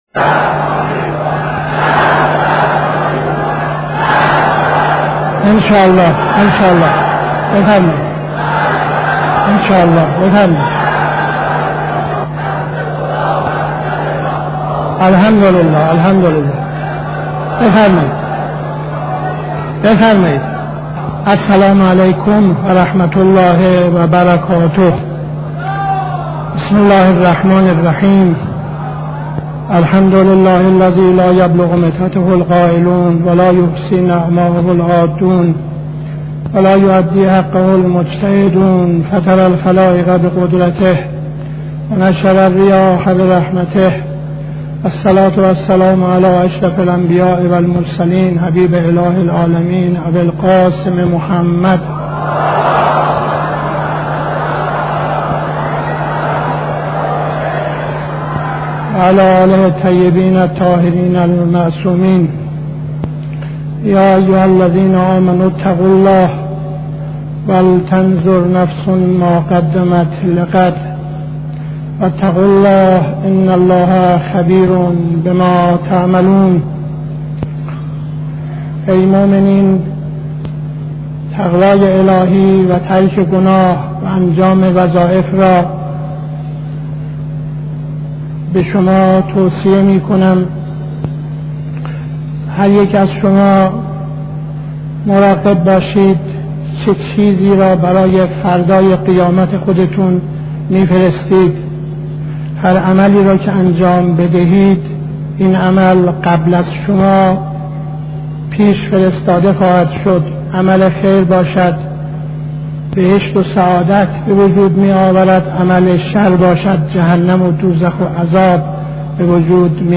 خطبه اول نماز جمعه 20-01-72